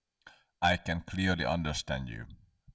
Mono channel
icanunderstand.wav